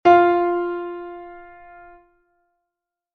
Consonancia Perfecta 8ª J (Fa - Fa)